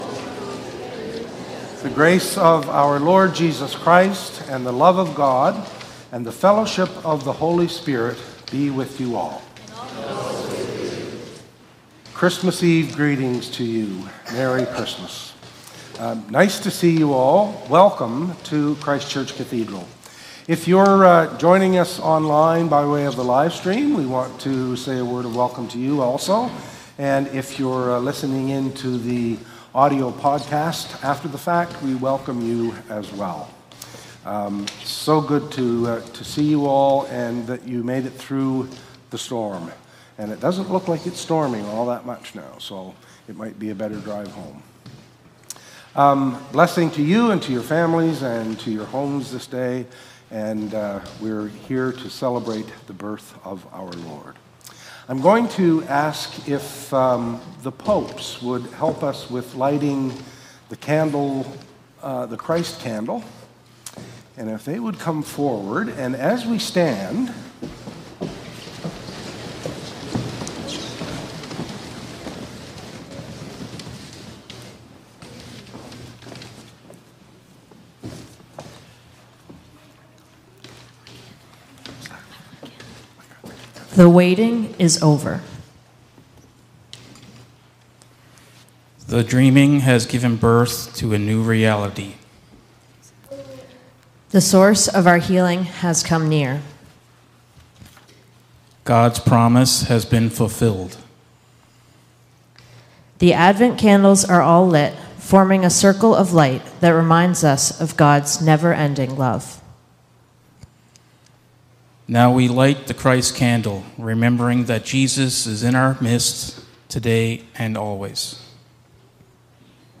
Worship and Sermon audio podcasts
WORSHIP - 4:00 p.m. Christmas Eve